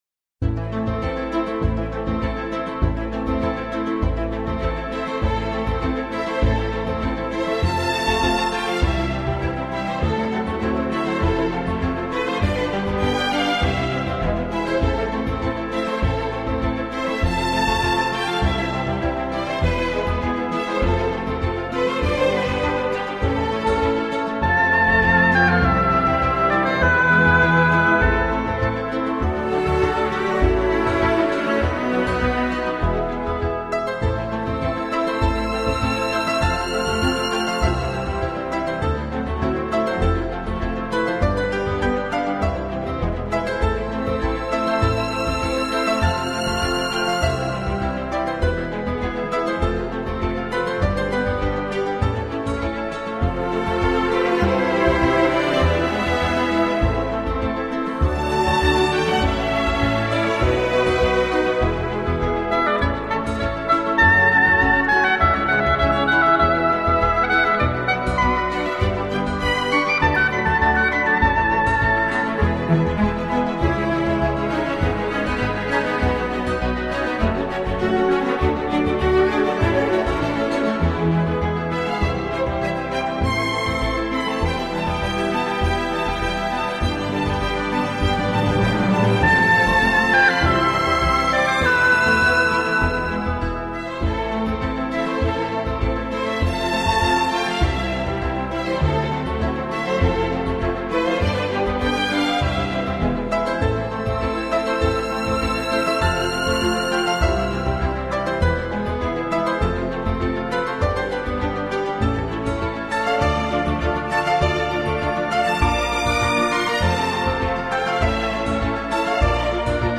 古典音乐